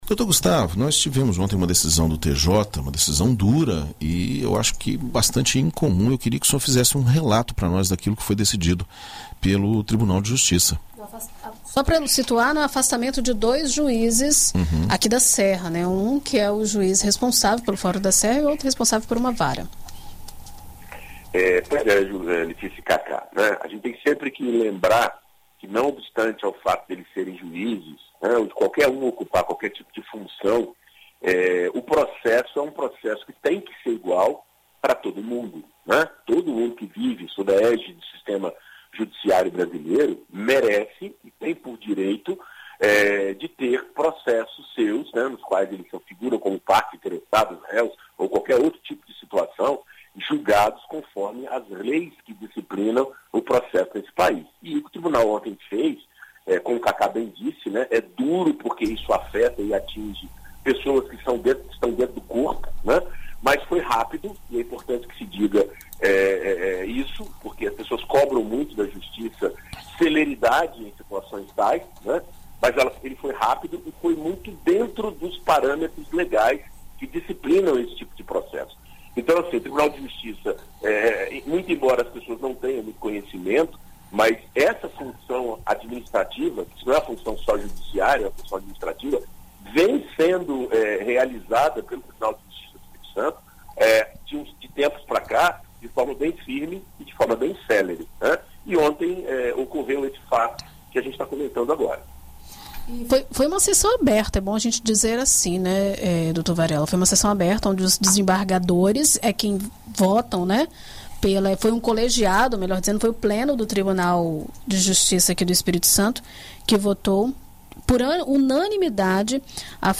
Direito para Todos: advogado explica afastamento de juízes da Serra